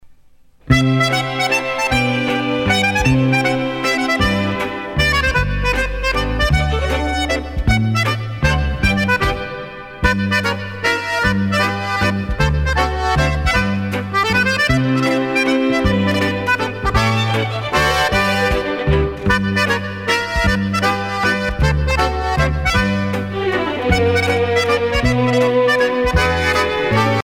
danse : java